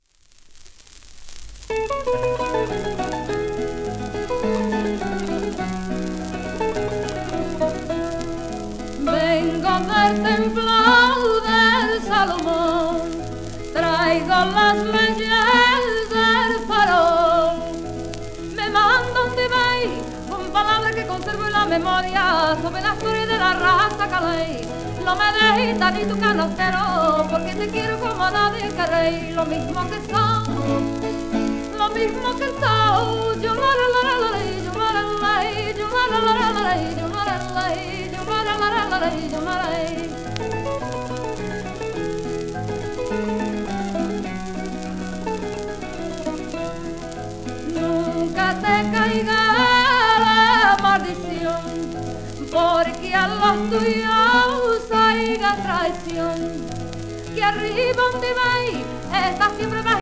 1952年頃？の録音
ポルトガルの女優・歌手。